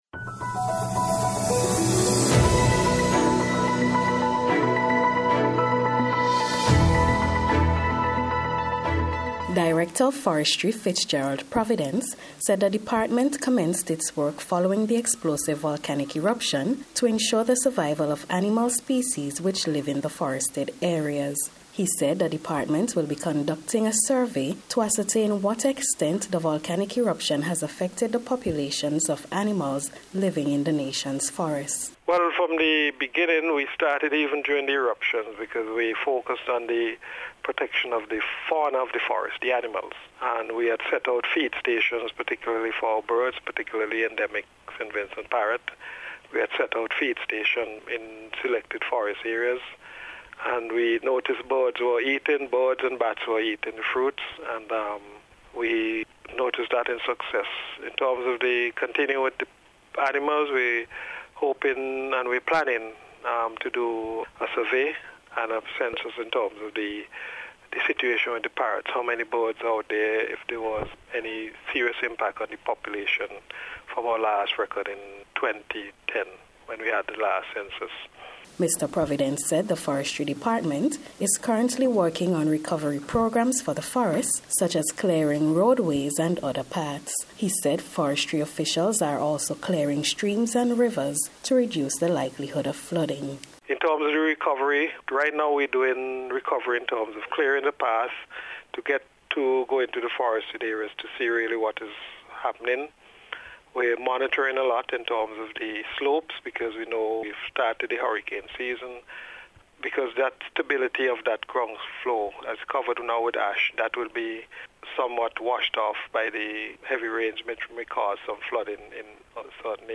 FORESTRY-PLANS-AFTER-VOLCANO-REPORT.mp3